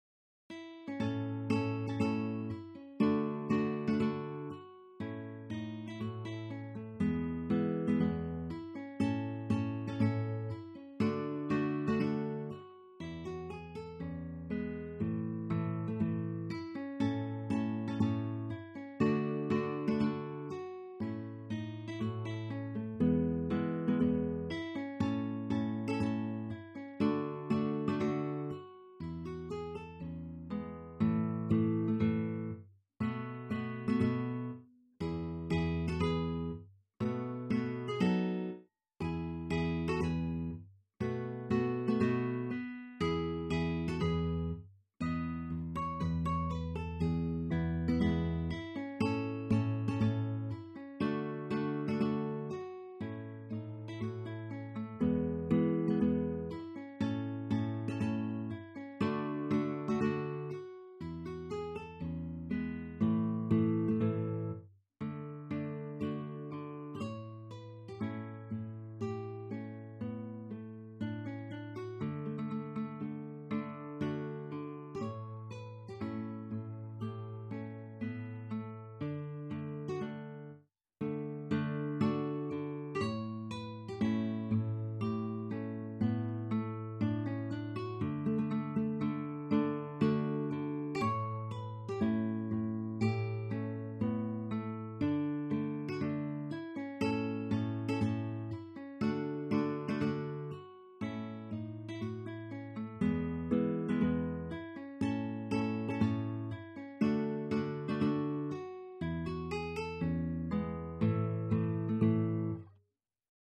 In effetti, la sua musica è scorrevole e piacevolissima e ve la consiglio per esercitazioni senza soverchie difficoltà.